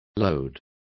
Complete with pronunciation of the translation of lodes.